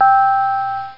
Chime High Sound Effect
chime-high.mp3